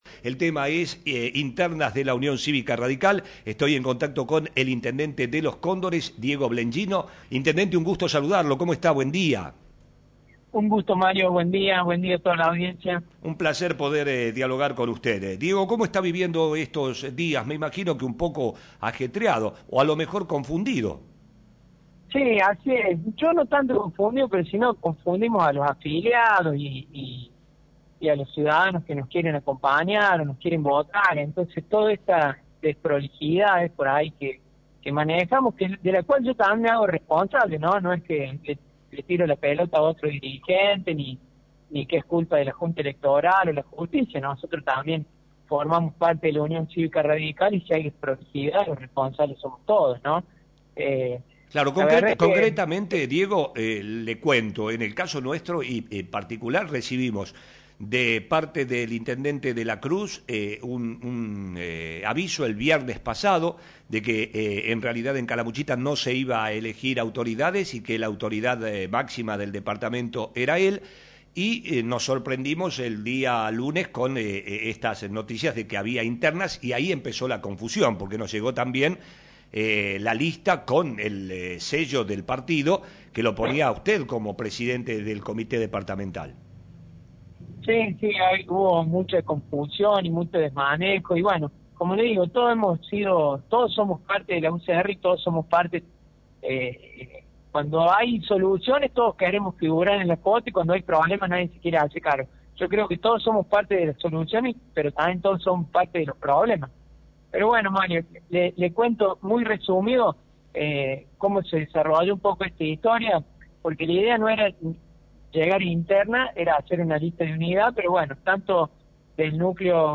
En diálogo con Flash FM el intendente de Los Cóndores, Diego Blengino dijo que no habrá internas en Calamuchita por lo que la autoridad máxima del partido a nivel regional es el intendente de La Cruz, Mauricio Jaime.